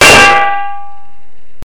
Metallic Clang
blacksmith bonk clang clonk hit impact iron metal sound effect free sound royalty free Memes